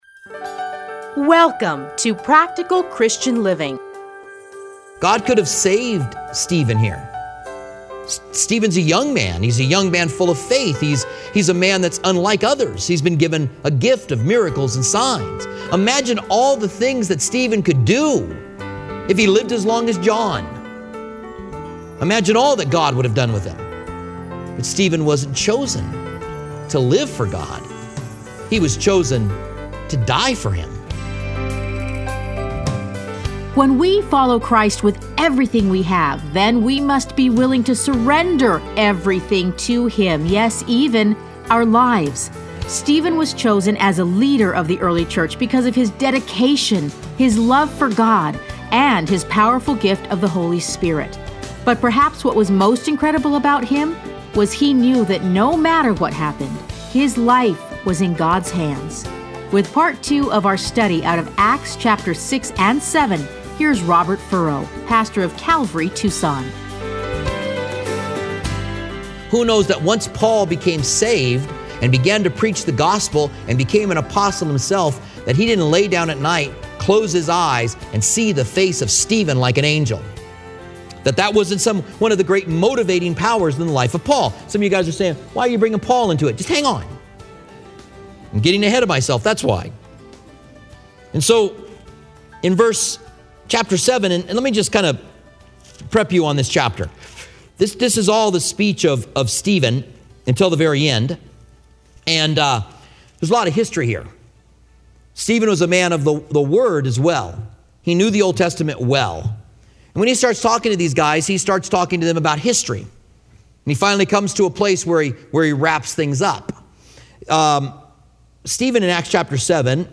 Listen to a teaching from Acts 6-7.